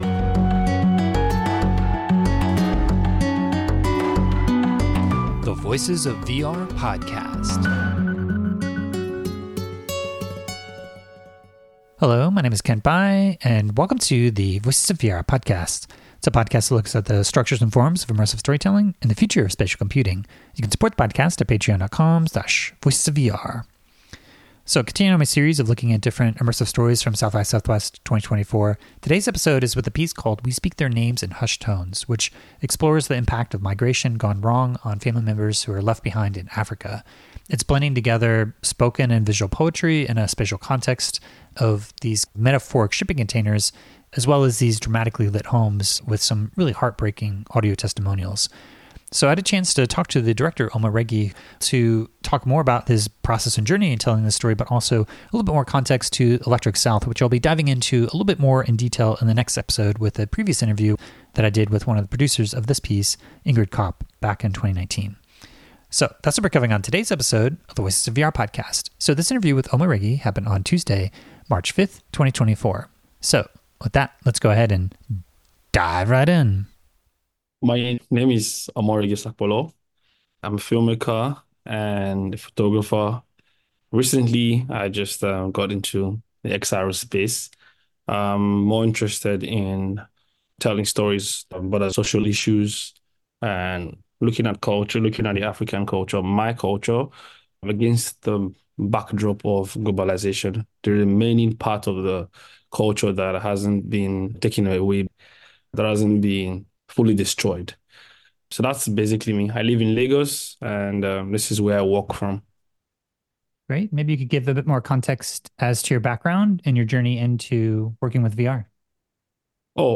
So this interview